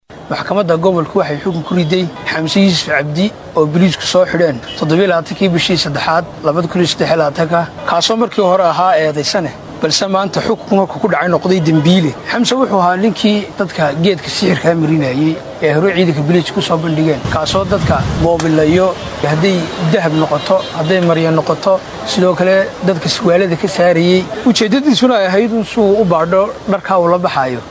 Mid ka mid ah saraakiisha booliiska Somaliland oo ninkan soo qabtay, ayaa sharraxaya qaabka uu eedeysanaha dadka u dhici jiray.